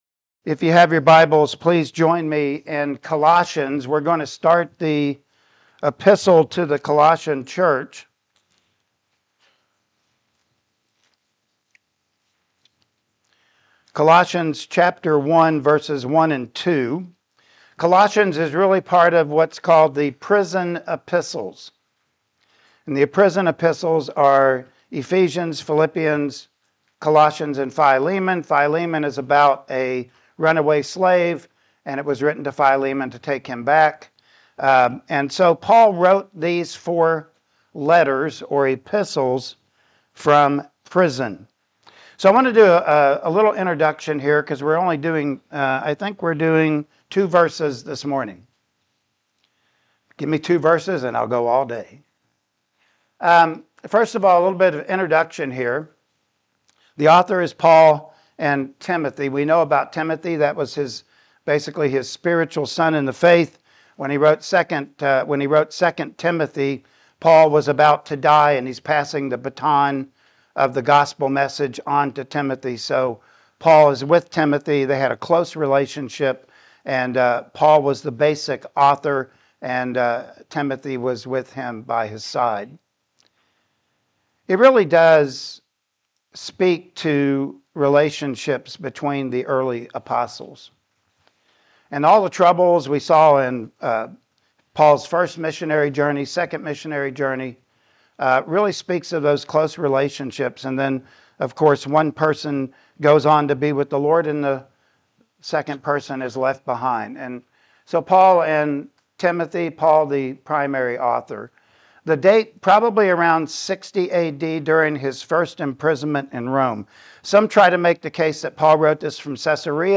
Colossians 1:1-2 Service Type: Sunday Morning Worship Service Topics